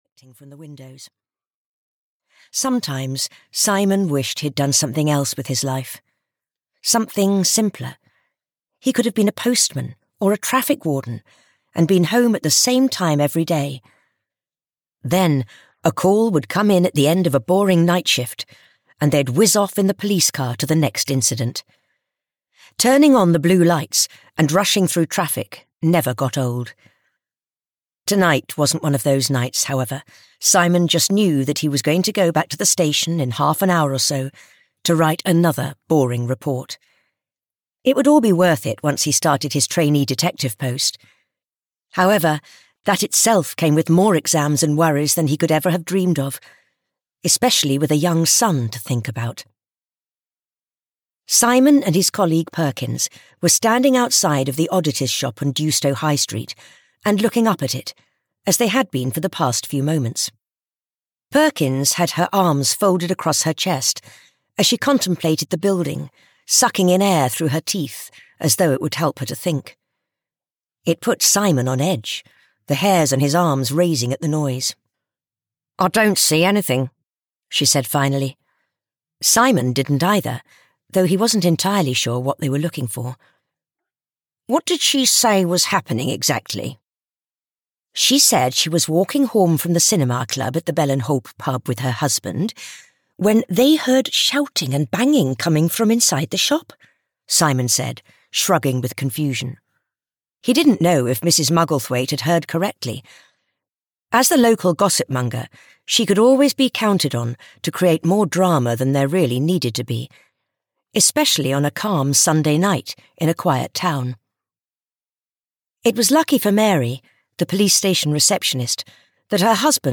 An Extremely Unlikely Death (EN) audiokniha
Ukázka z knihy